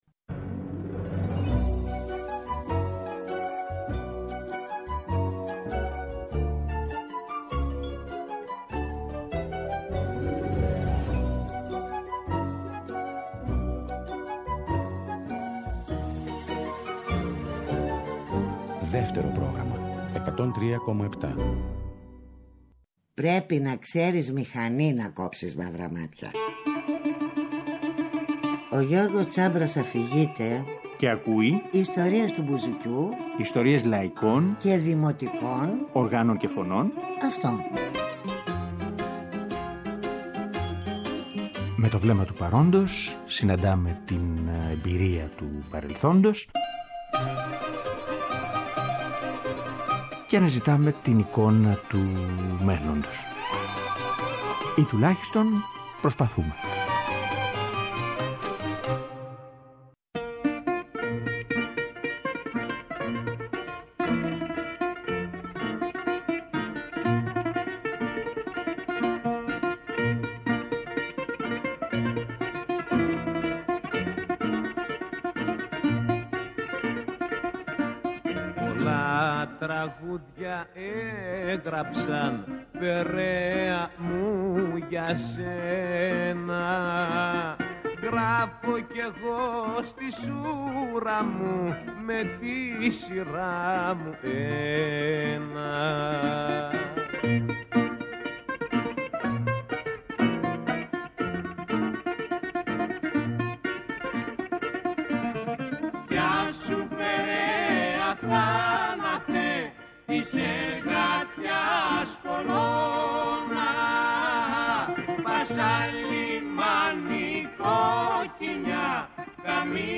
Τρίτη 16/5/23, 9 με 10 το βράδυ, στο Δεύτερο Πρόγραμμα